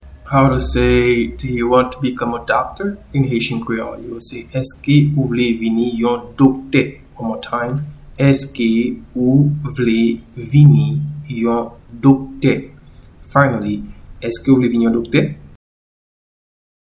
Pronunciation and Transcript:
Do-you-want-to-become-a-doctor-in-Haitian-Creole-Eske-ou-vle-vini-yon-dokte-1.mp3